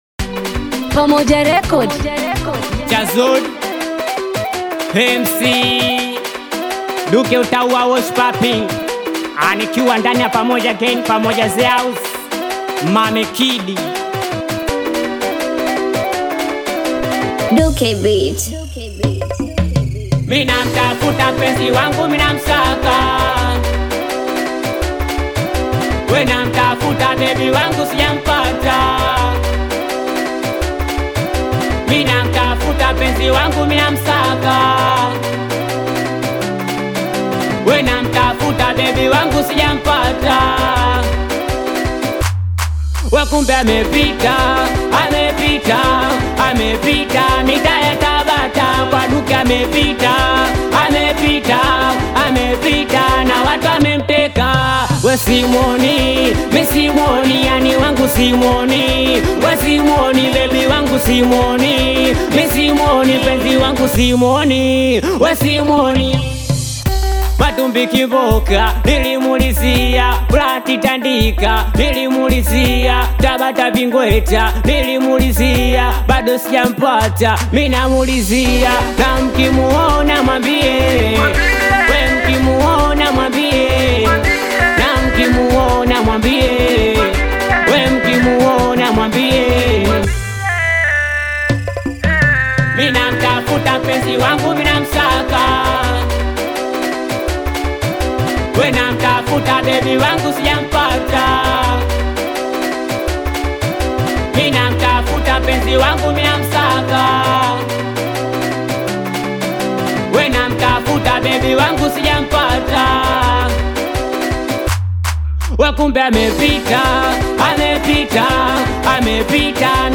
SINGELI MUSIC